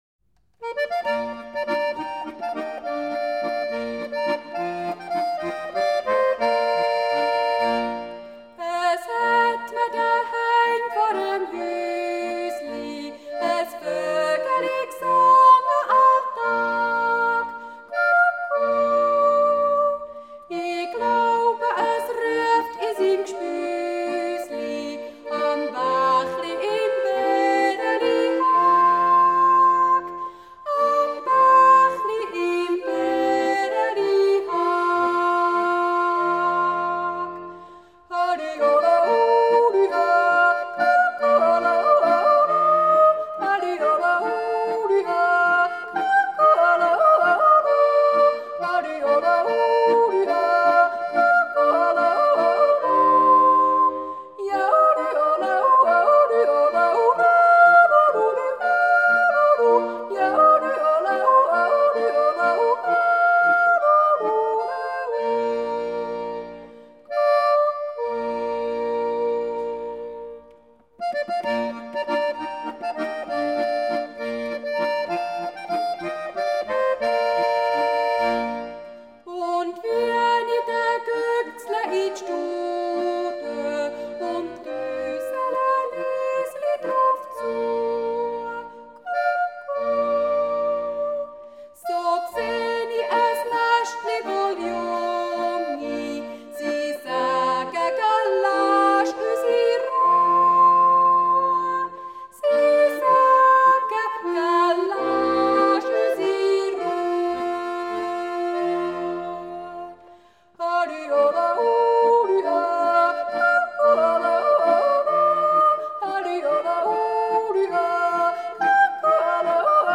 A Swiss National Yodeling Festival
Solo yodel performance